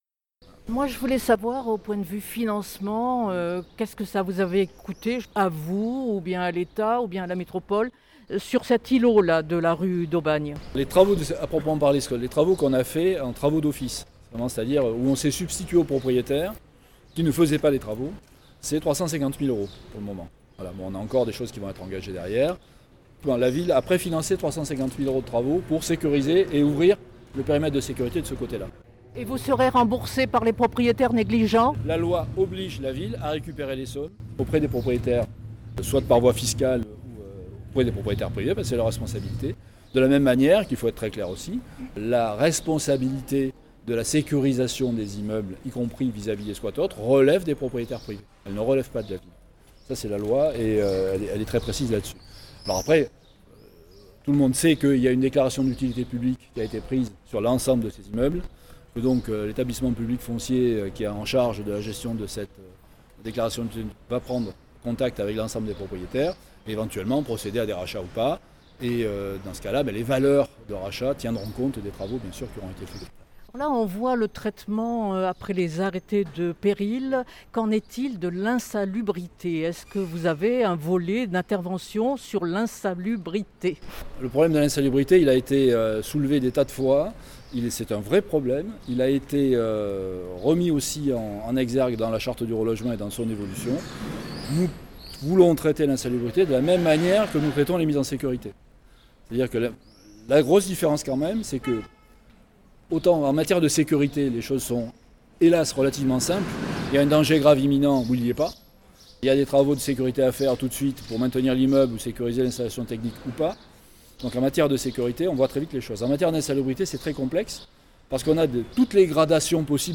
Au cours de cette matinée, sur un bout de trottoir, l’élu est interpellé par des habitants de la rue: «Tout ce qu’on veut c’est retrouver un petit peu de semblant de vie normale.